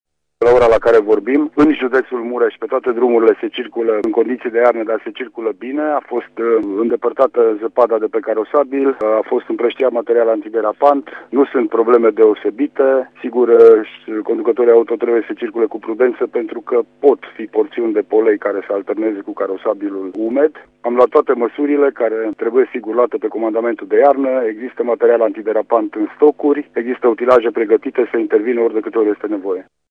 Prefectul de Mureş, Lucian Goga, a precizat că astăzi, la ora prânzului, starea drumurilor din judeţ permitea circulaţia în condiţii de iarnă, fără a fi probleme deosebite.El a spus că pe întreg judeţul Mureş stocurile de substanţe antiderapante sunt suficiente, iar utilajele sunt gata să intervină în caz de nevoie.